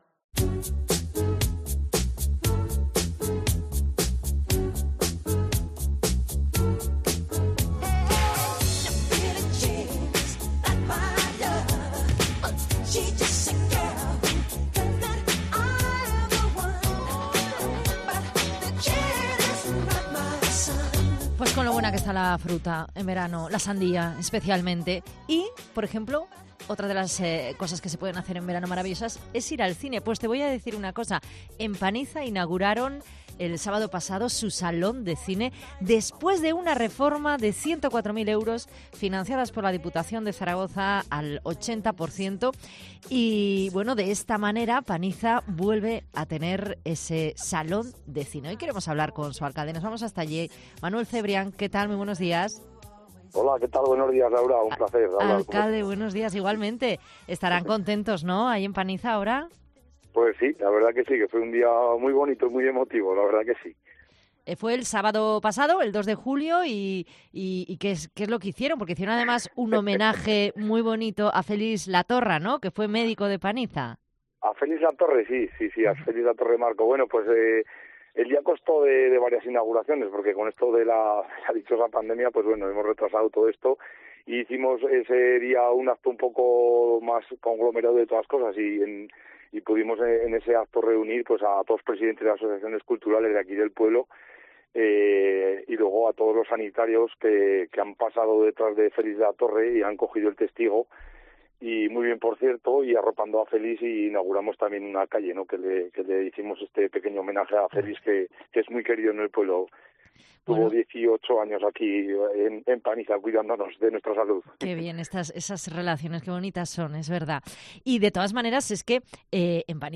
Hablamos con Manuel Cebrián, alcalde de Paniza, sobre la reapertura del cine de la localidad